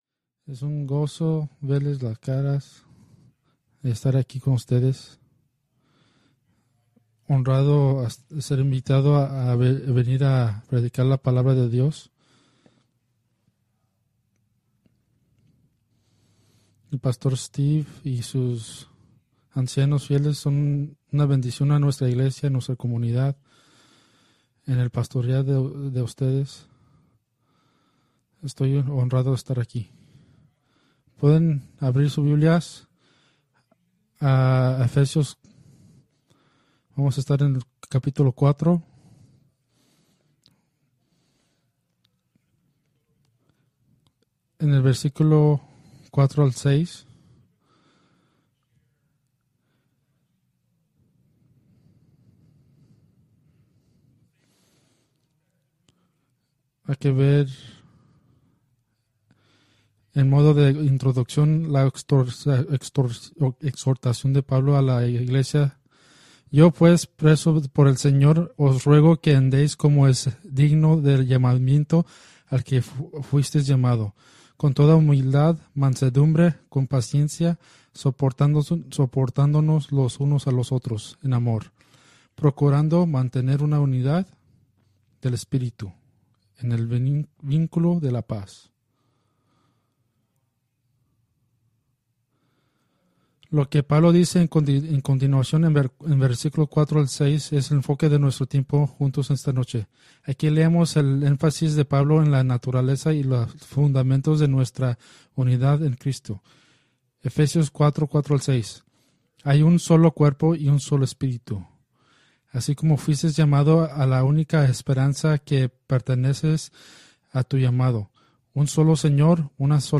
Preached August 11, 2024 from Ephesians 4:4-6